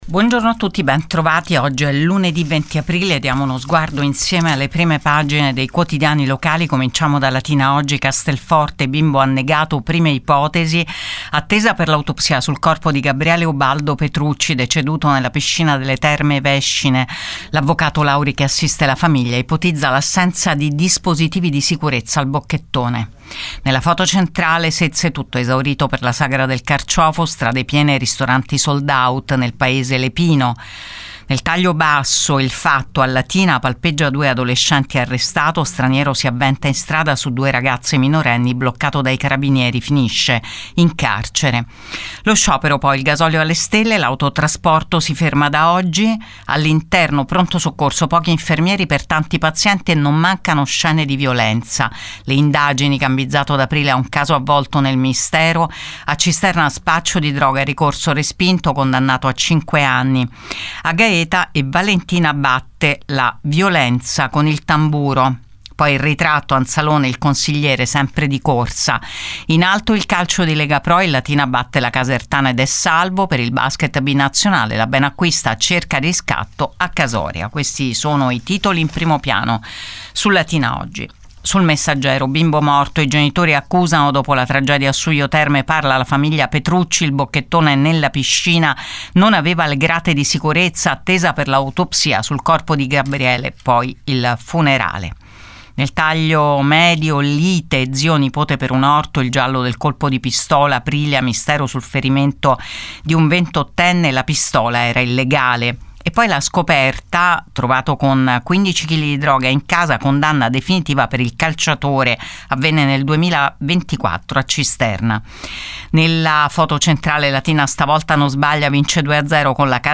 LATINA – Ogni giorno, dal lunedì al venerdì alle 7,00 e in replica alle 7,30 e il sabato solo alle 7,30 è in onda su Radio Luna, Prima Pagina, uno sguardo ai titoli dei quotidiani locali in edicola.